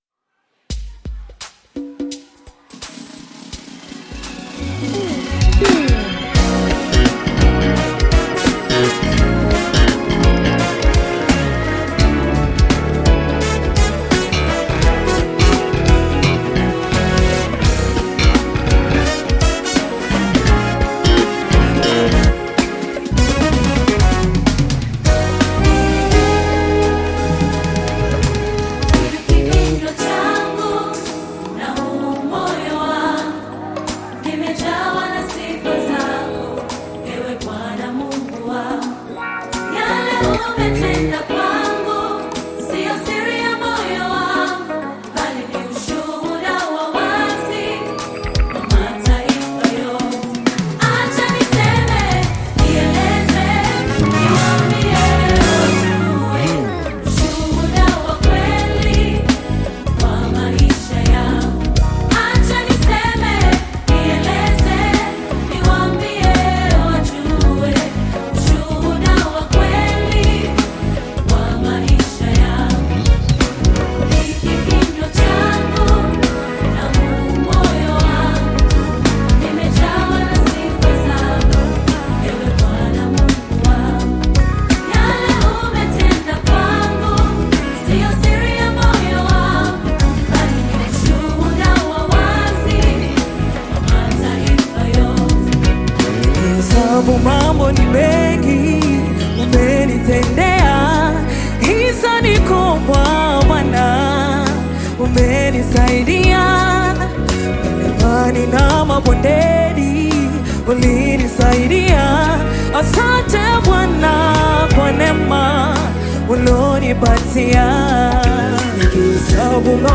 Gospel music track
Tanzanian Gospel group